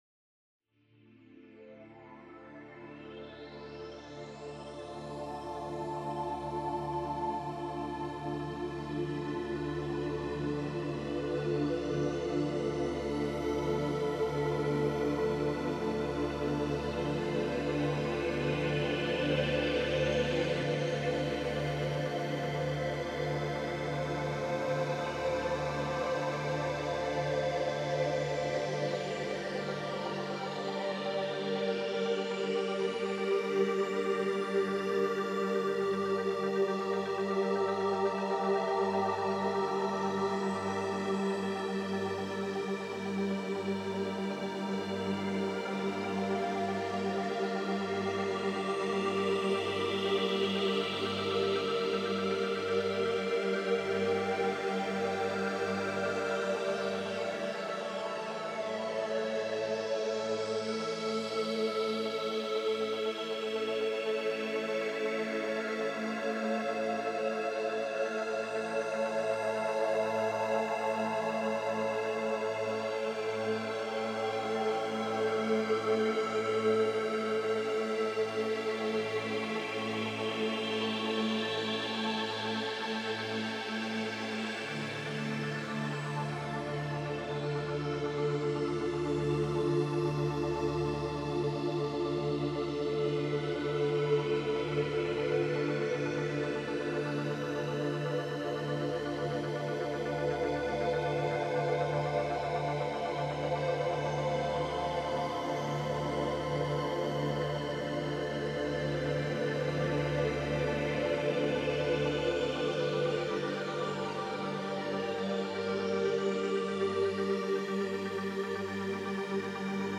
Музыка для исцеления и стабилизации нервной системы